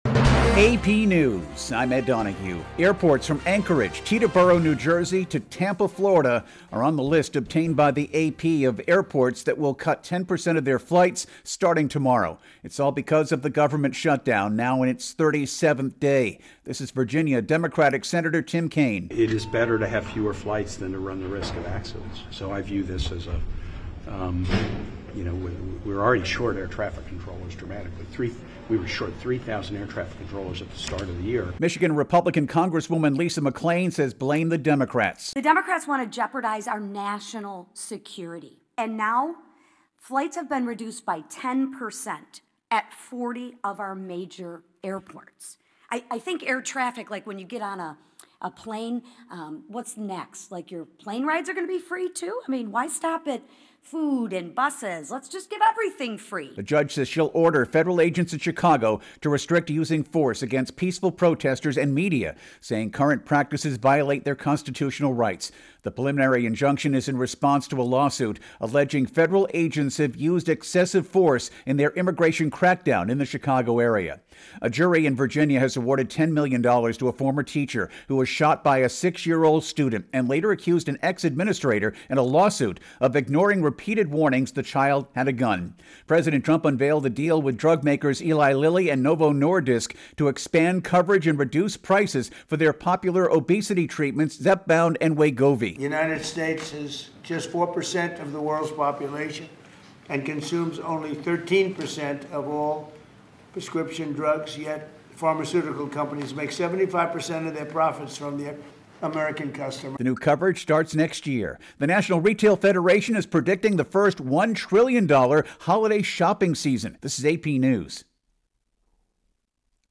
AP Hourly NewsCast